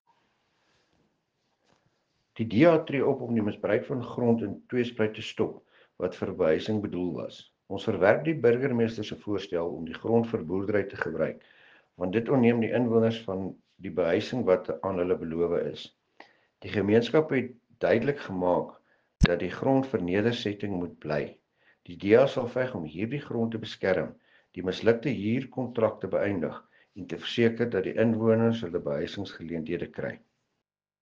Afrikaans soundbite by Cllr Dewald Hattingh.